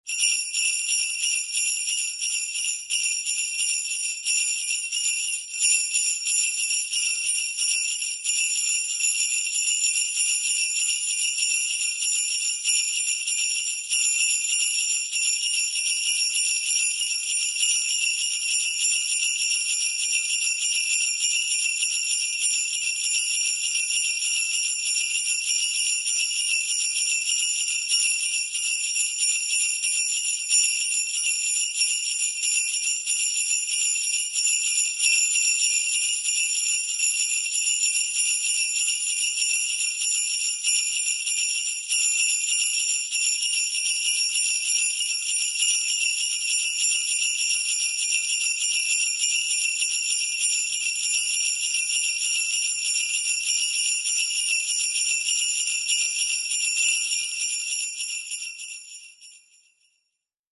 Sleigh Bell 3 Sound Effect Free Download